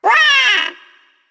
One of Yoshi's voice clips in Mario Party 6